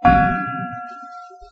extinguisher_touch.2.ogg